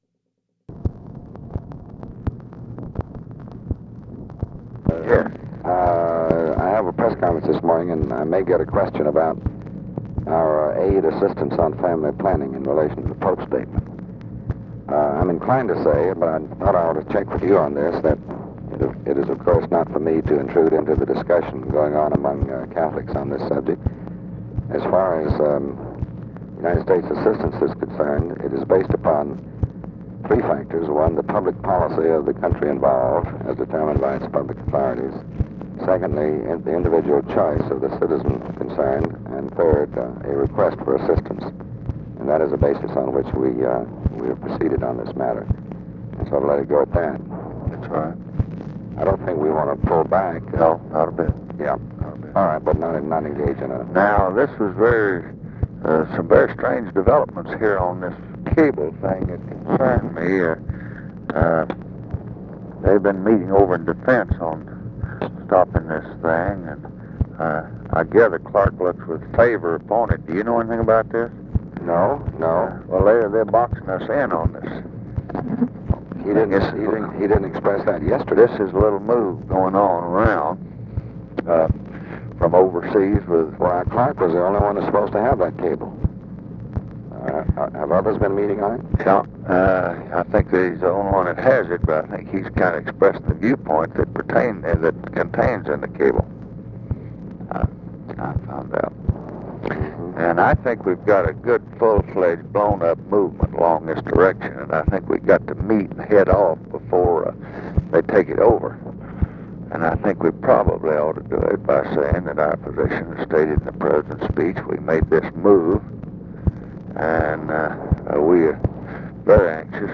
Conversation with DEAN RUSK, July 30, 1968
Secret White House Tapes